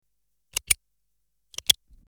Pen Click 02
Pen_click_02.mp3